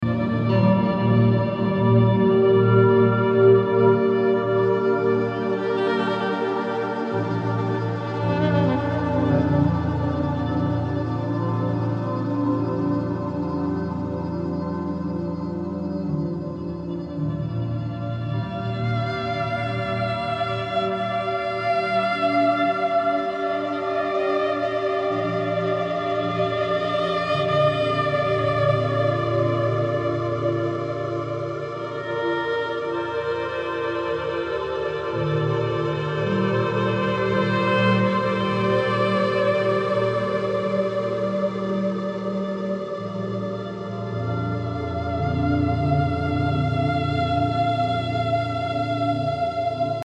Ambient, Drone >
Post Classical >